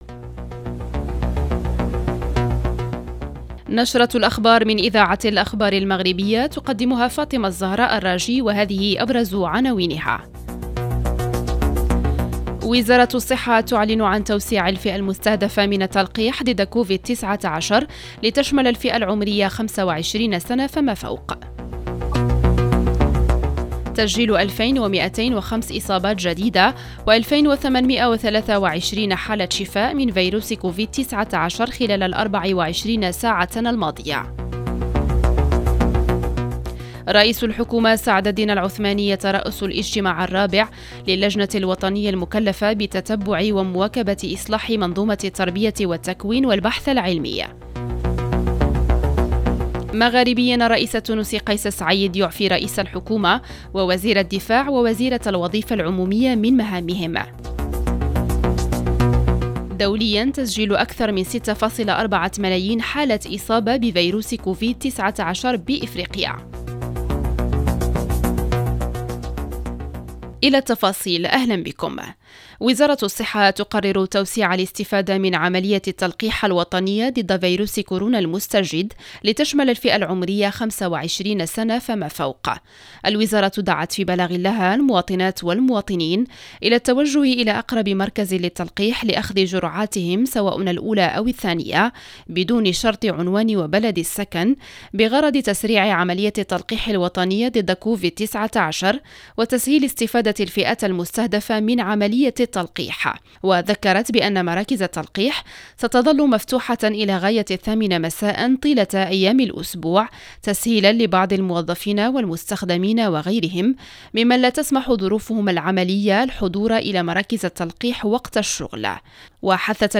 Bulletins d'information